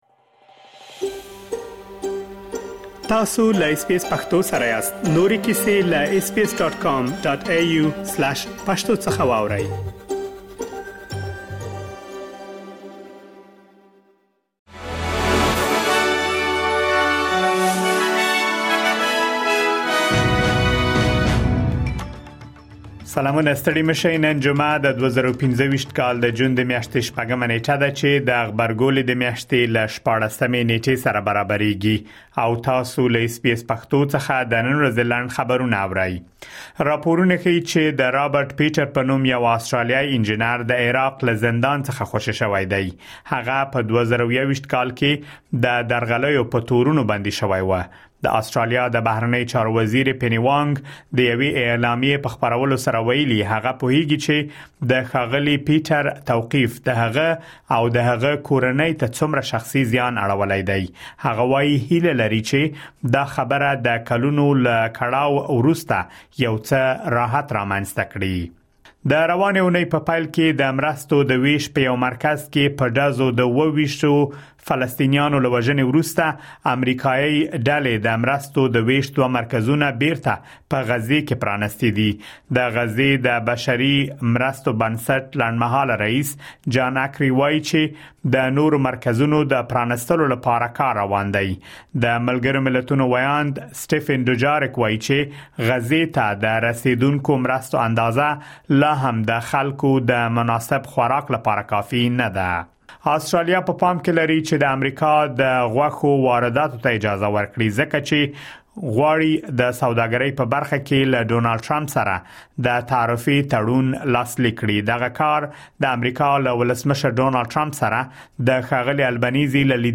د اس بي اس پښتو د نن ورځې لنډ خبرونه | ۶ جون ۲۰۲۵